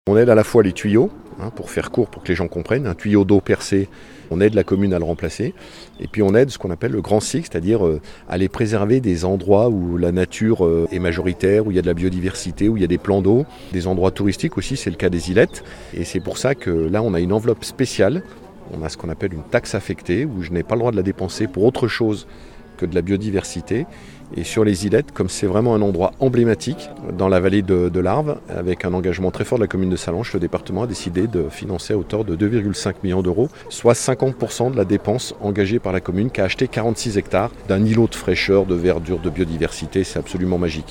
Cet investissement entre dans le cadre d'une politique globale, comme l’explique le président du Conseil départemental, Martial Saddier.
ITG Martial Saddier 2 - requalification lac des ilettes_WM.mp3